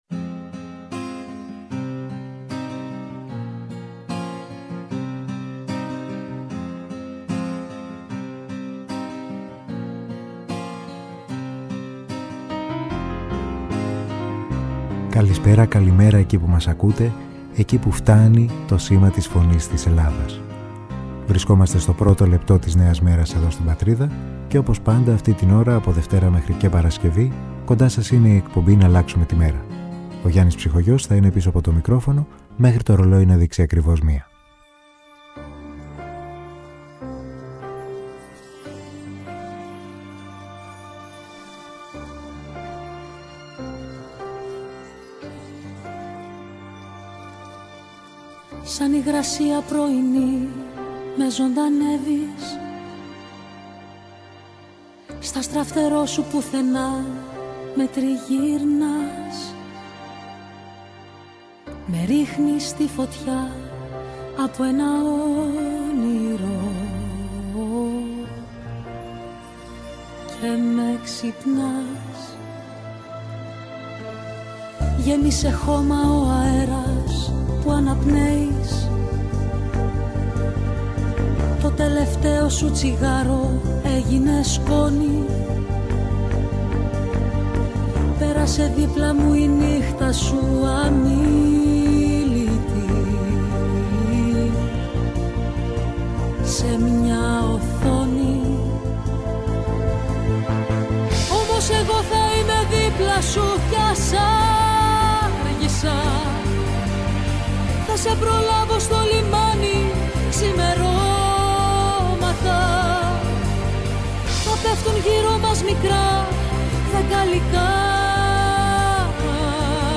μαζί με τις ακροάτριες και τους ακροατές του παγκόσμιου ραδιοφώνου της ΕΡΤ «Η Φωνή της Ελλάδας»
Μουσική